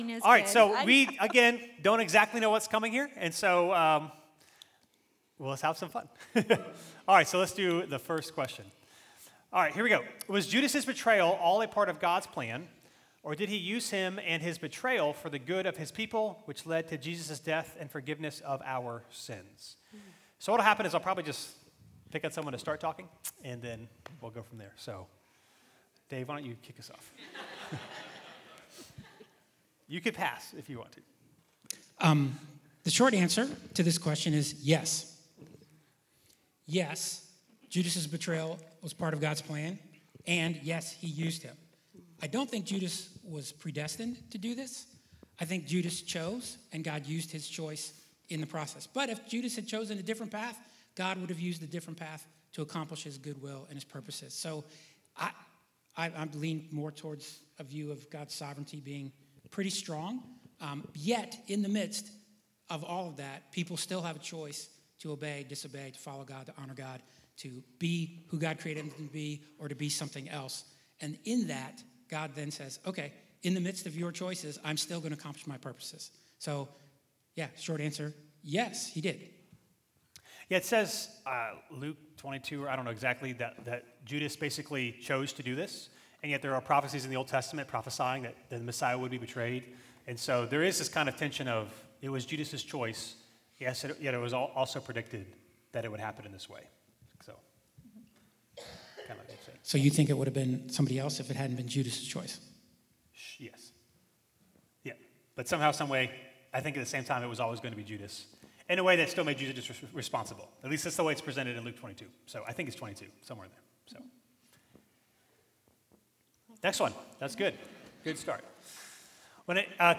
Live Q & A Sunday